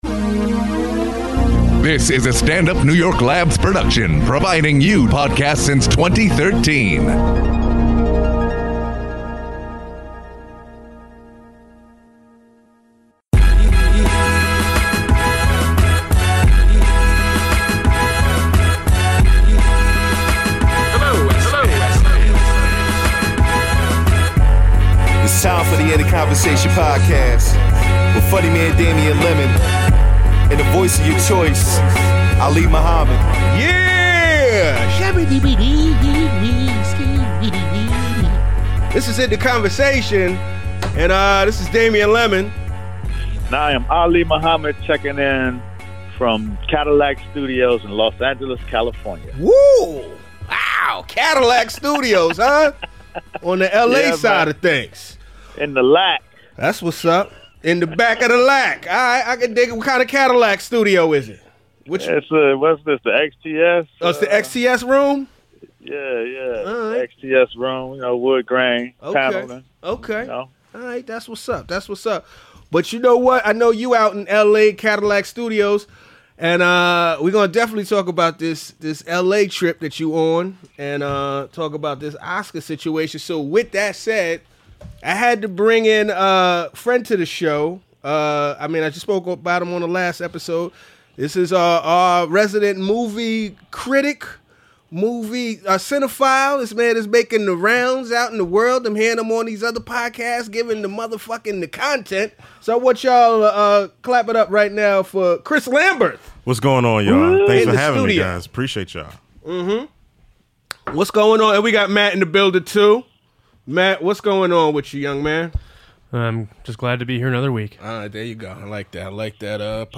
calling in from LA